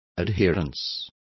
Complete with pronunciation of the translation of adherences.